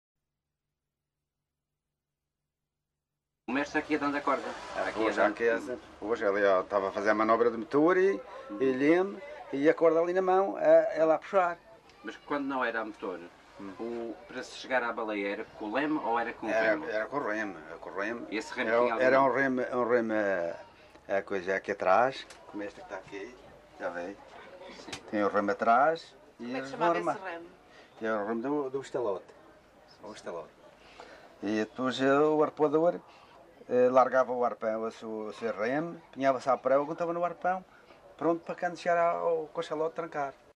LocalidadeCaniçal (Machico, Funchal)